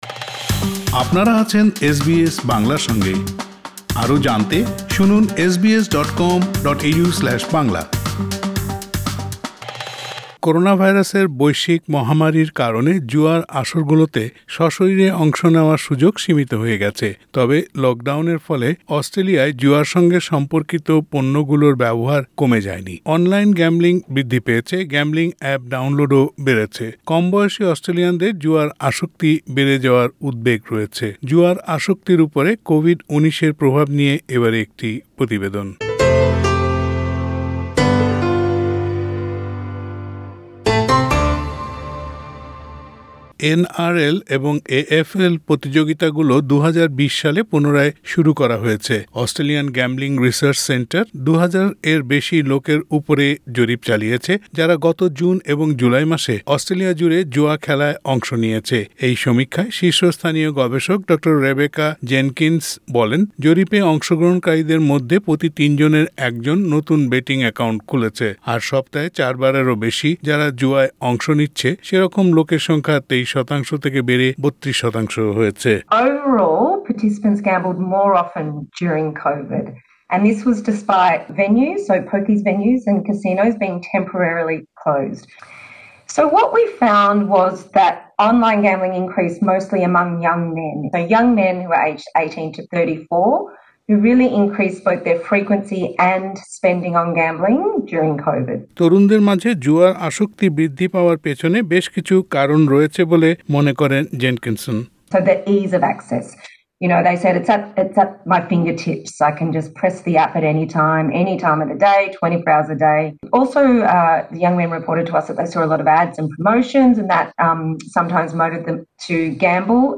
অনলাইন গ্যাম্বলিং বৃদ্ধি পেয়েছে, গ্যাম্বলিং অ্যাপ ডাউনলোডও বেড়েছে। কমবয়সী অস্ট্রেলিয়ানদের জুয়ায় আসক্তি বেড়ে যাওয়ায় উদ্বেগ বেড়েছে। প্রতিবেদনটি শুনতে উপরের অডিও প্লেয়ারের লিংকটিতে ক্লিক করুন।